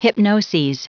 Prononciation du mot hypnoses en anglais (fichier audio)
Prononciation du mot : hypnoses